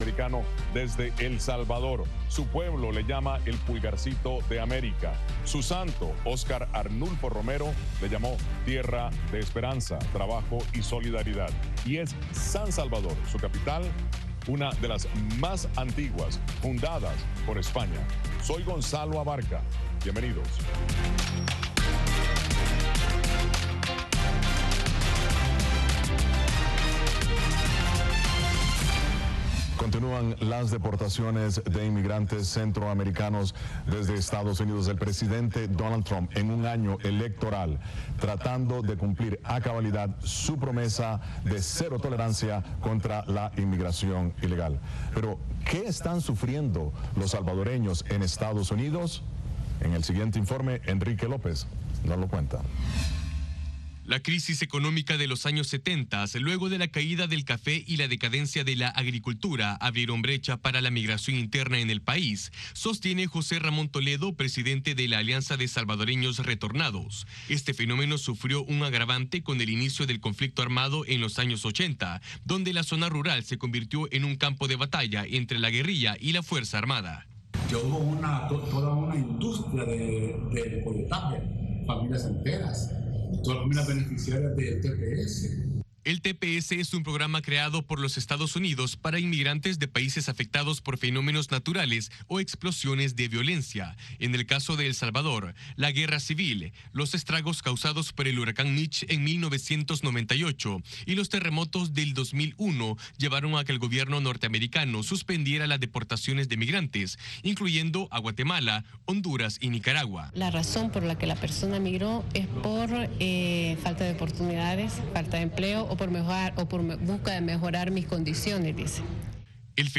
Programa de análisis de treinta minutos de duración con expertos en diversos temas.